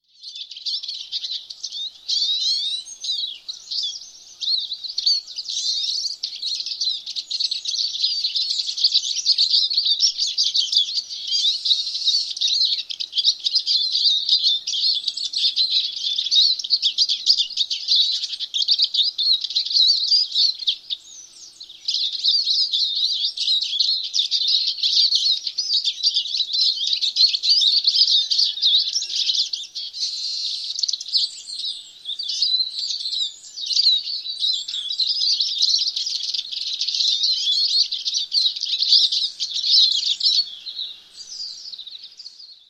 sijsje.mp3